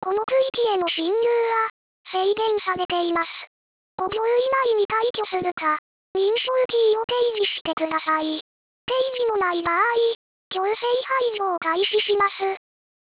それからこれも瑣末な部分ですが、警告音声は aplay で再生する予定でした。
声質は趣味です。
caution.wav